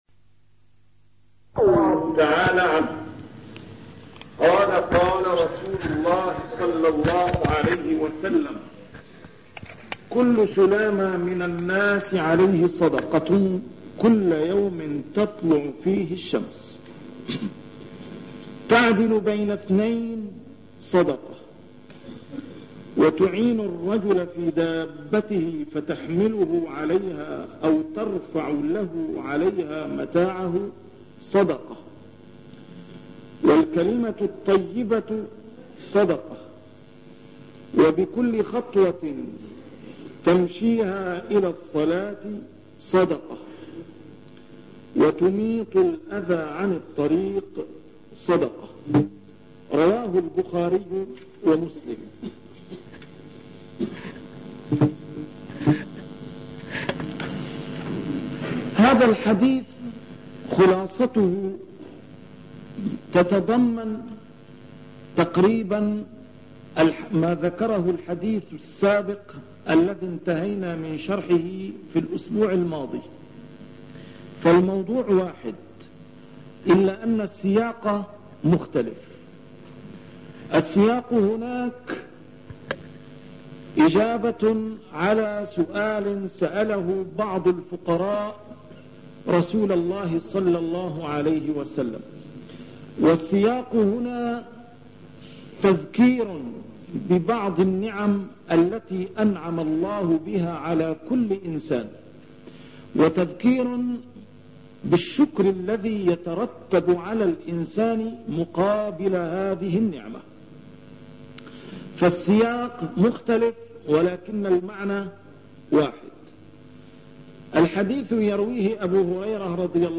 A MARTYR SCHOLAR: IMAM MUHAMMAD SAEED RAMADAN AL-BOUTI - الدروس العلمية - شرح الأحاديث الأربعين النووية - بداية شرح الحديث السادس والعشرون: حديث أبي هريرة (كلُّ سُلامَى مِنَ الناسِ عليهِ صدقةٌ) 86